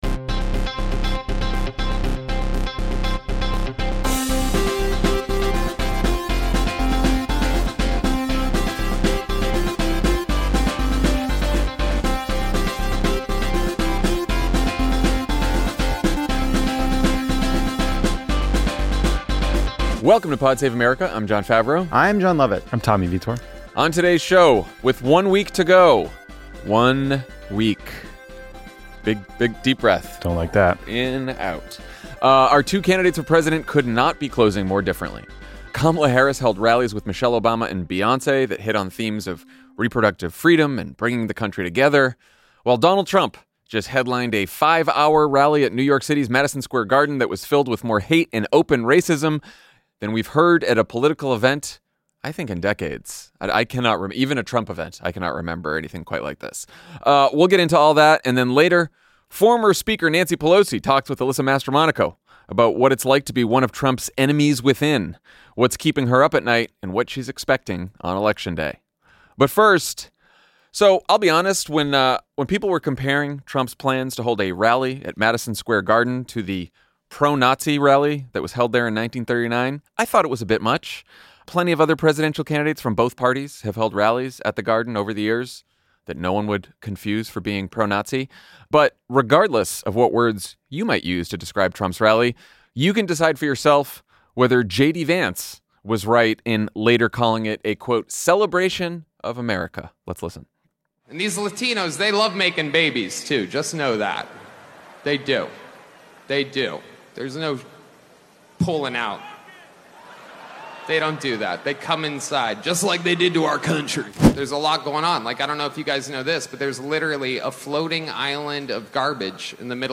Jon, Lovett, and Tommy react to a packed weekend of campaigning and discuss how the Harris campaign is making the final sale. Then, Alyssa Mastromonaco talks with former Speaker Nancy Pelosi about what it's like being one of Trump's "enemies within," what's keeping her up at night, and what she's expecting on Election Day.